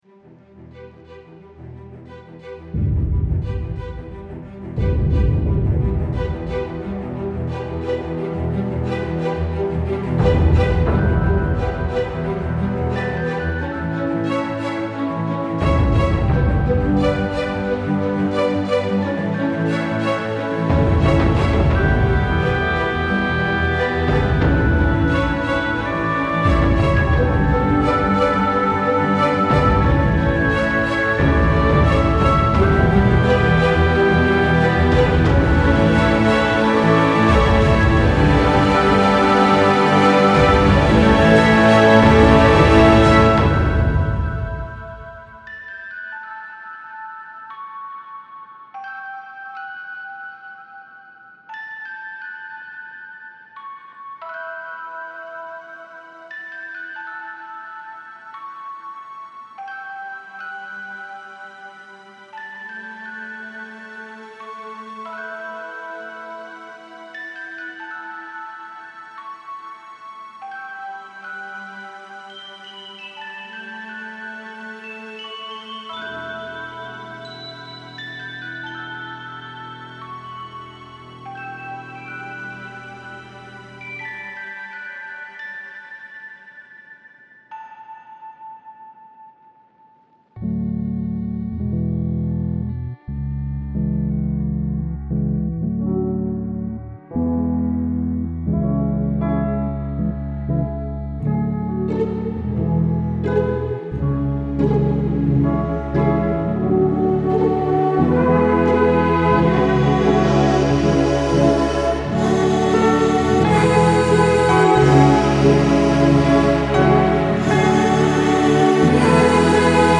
从钢琴到弦乐，竖琴，牛角，长笛等等！
这种免版税的音色库具有各种各样的管弦乐器：从钢琴到弦乐器，竖琴，长笛，吉他，号角和celesta。
子冲击力和打击乐声也包括在内。